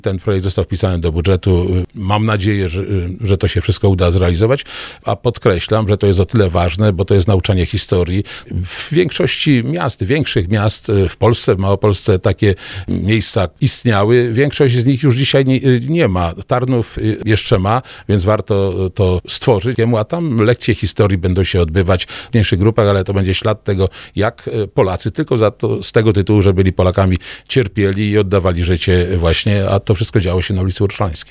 O planach na stworzenie takiego miejsca pamięci mówił w rozmowie Słowo za Słowo wicemarszałek województwa małopolskiego Ryszard Pagacz.